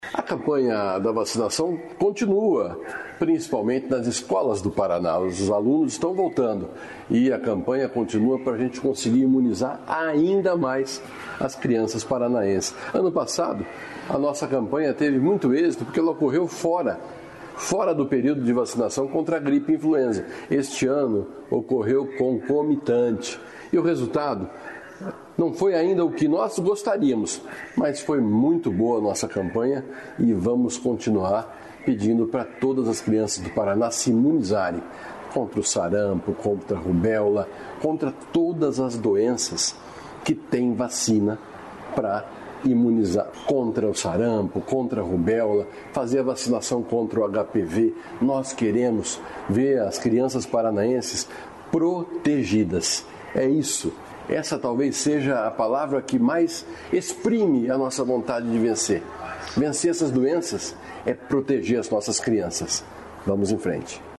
Sonora do secretário da Saúde, Beto Preto, sobre vacinação nas escolas